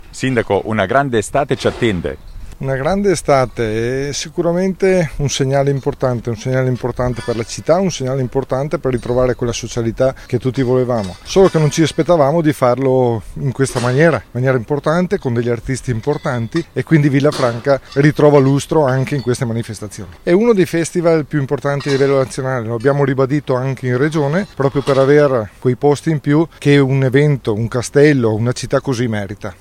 Il sindaco di Villafranca Roberto Luca Dall’Oca:
Sindaco-villafranca.mp3